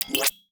UIClick_Equip Power Up Exit 02.wav